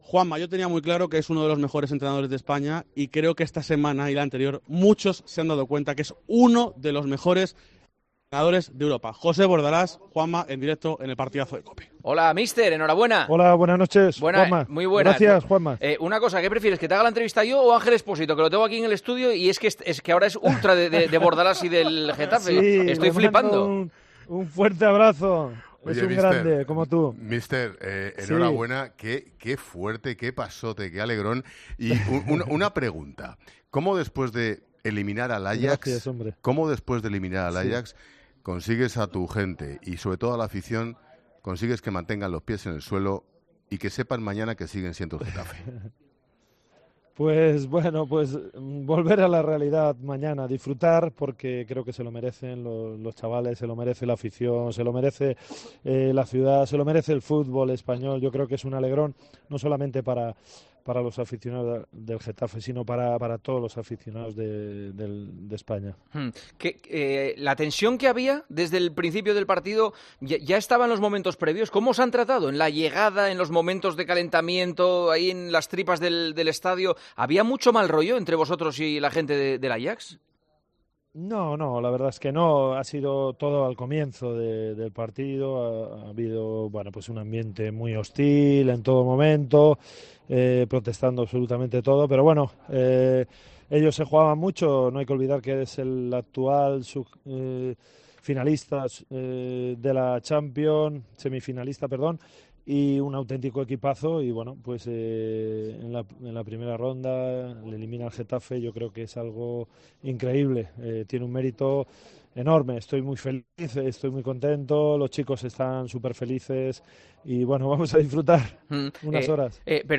El entrenador del Getafe charló con Juanma Castaño y Ángel Expósito tras eliminar al Ajax, "en un ambiente hostil".